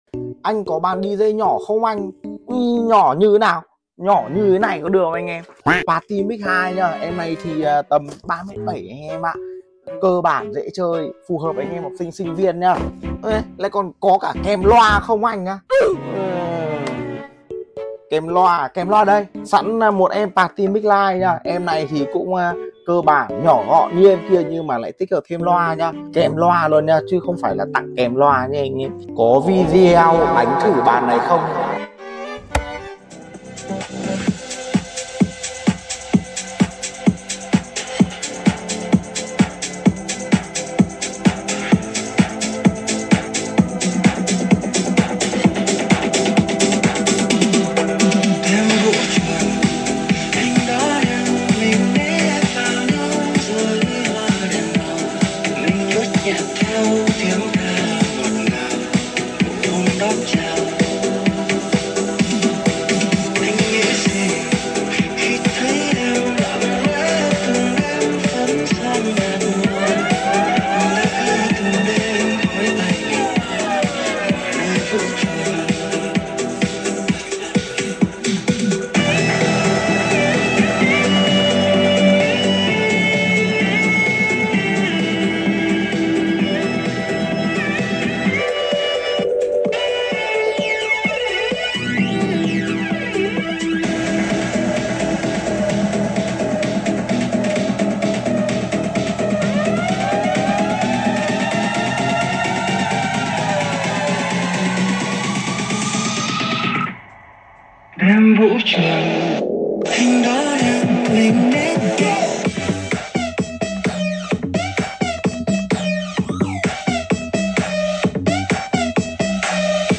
Mix nhạc cơ bản trên sound effects free download